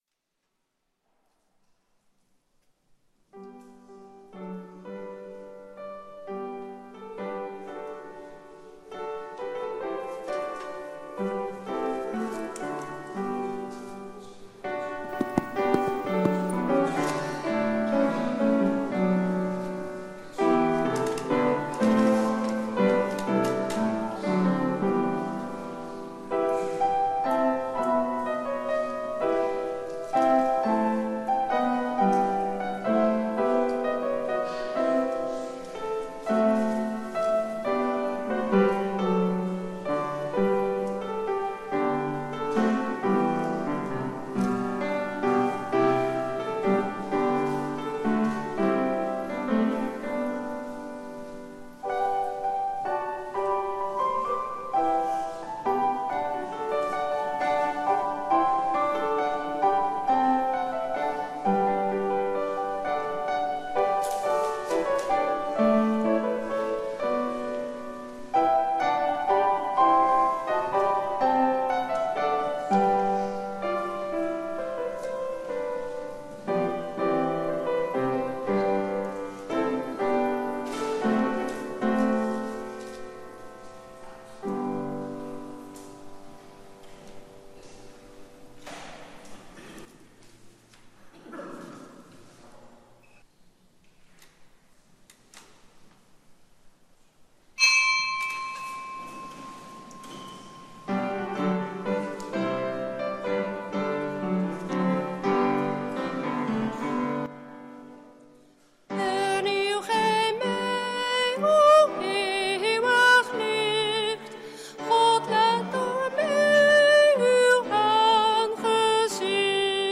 Lezingen